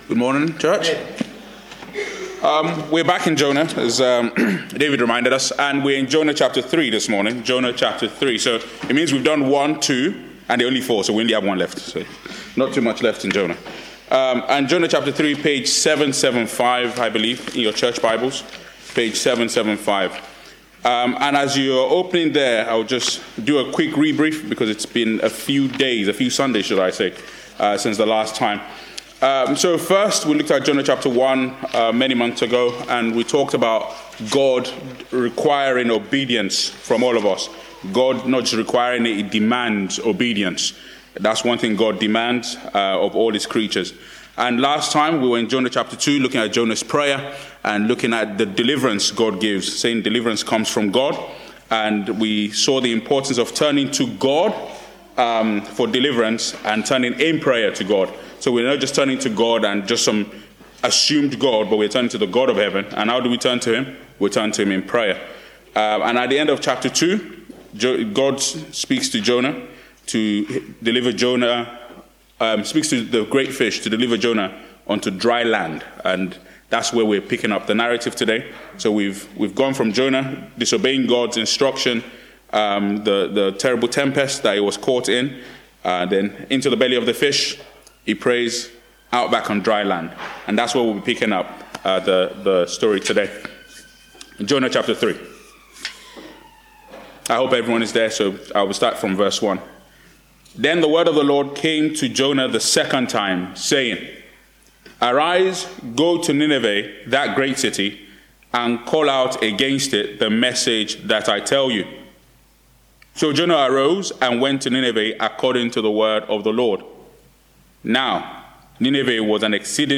Jonah 3:1-10 Service Type: Preaching Disaster foretold